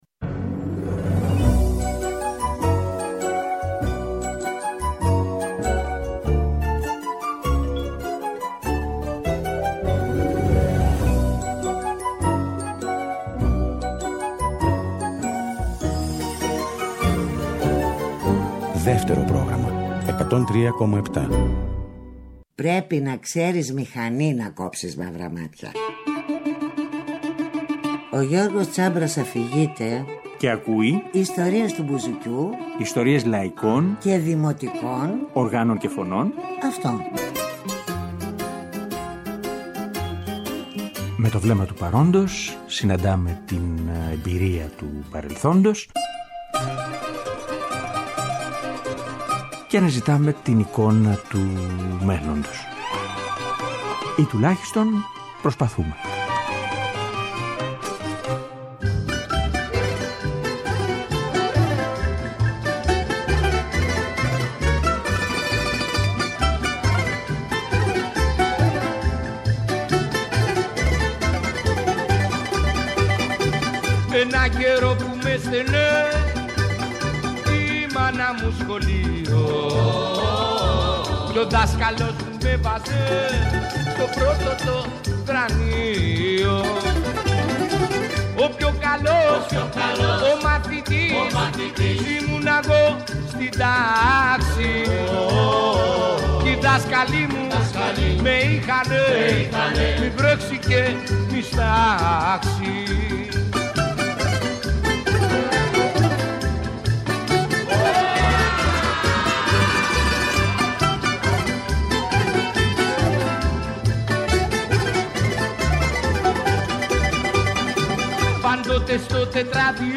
καντάδες